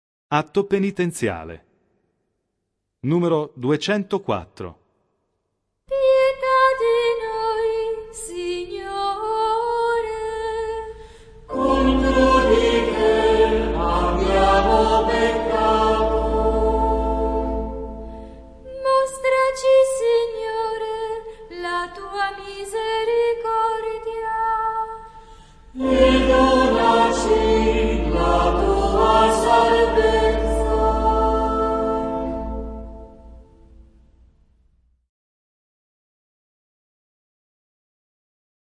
Vi invitiamo a soffermarvi su una piccola novità, prevista dal Messale: introduciamo il canto dell'Atto Penitenziale nella II Forma: